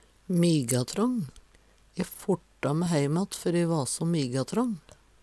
migatrång - Numedalsmål (en-US)